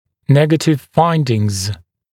[‘negətɪv ‘faɪndɪŋz][‘нэгэтив ‘файндинз]обнаруженные негативные явления